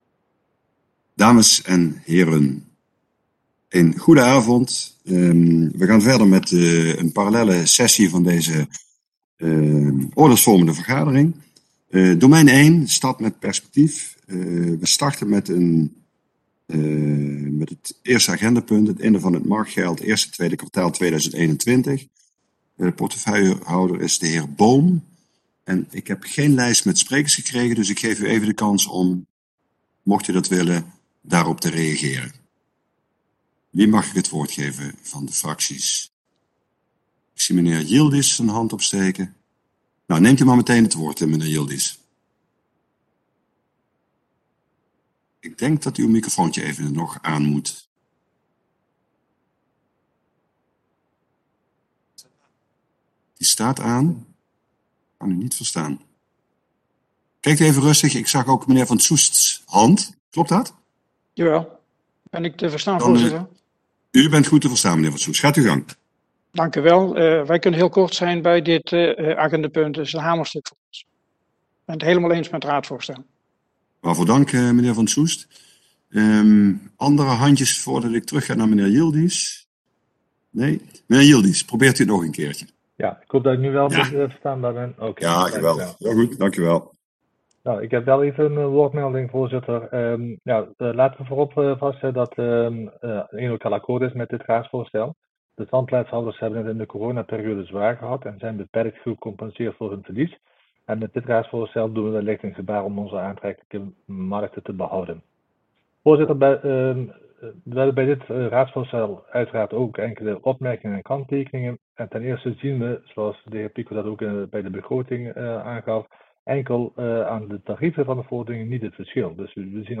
Oordeelsvormende raadsvergadering 10 november 2021 19:00:00, Gemeente Venlo
Stadhuis Raadzaal